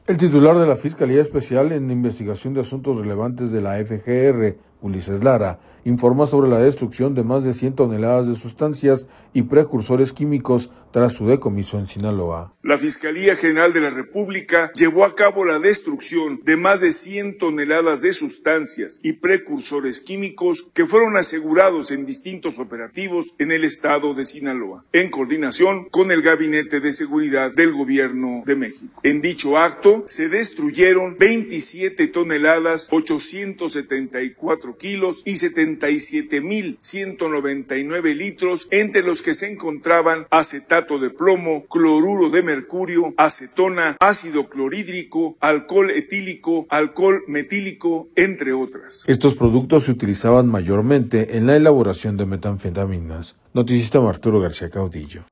El titular de la Fiscalía Especial en Investigación de Asuntos Relevantes de la FGR, Ulises Lara, informa sobre la destrucción de más de cien toneladas de sustancias y precursores químicos tras su decomiso en Sinaloa.